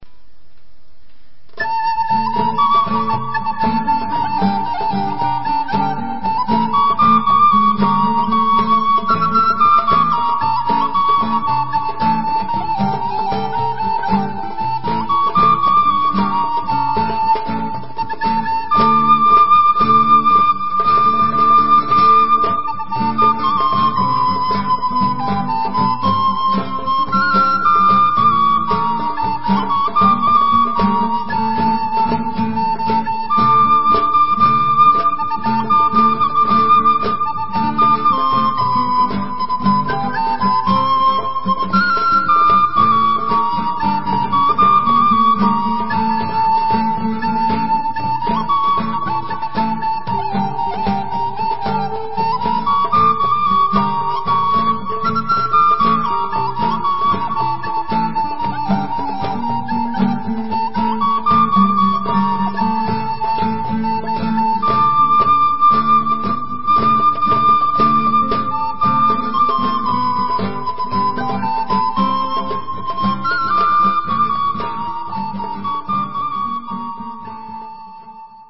כלים: גיטרה, חלילית, דרבוקה.
נגינה: אני (גיטרה), עצמי (חלילית), ואנכי (דרבוקה)